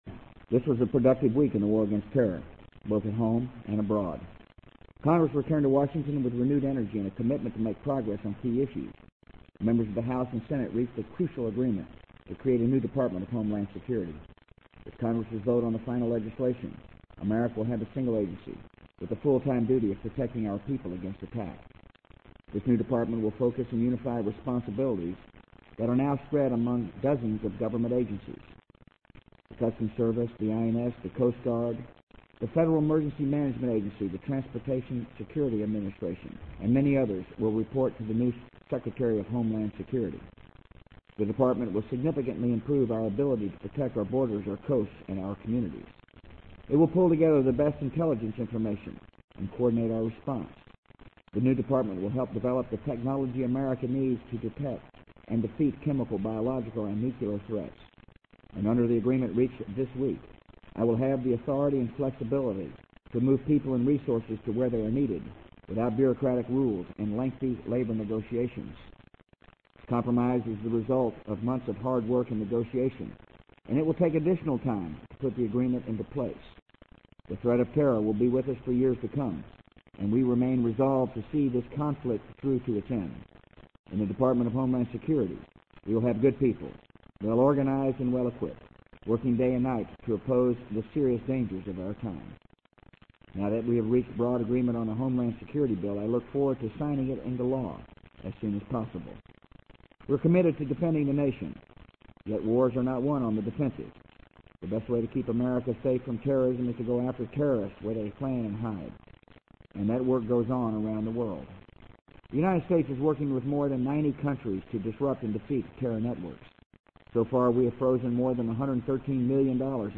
【美国总统George W. Bush电台演讲】2002-11-16 听力文件下载—在线英语听力室